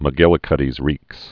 (mə-gĭlĭ-kŭdēz rēks)